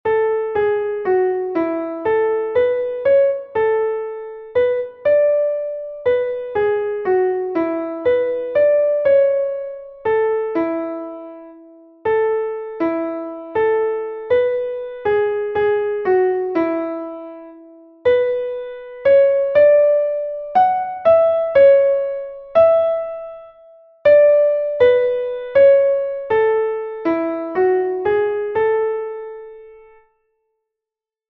Key of A major, three sharps in the key signature (F-sharp, C-sharp, and G-sharp).
Exercise 4 rhythmic reading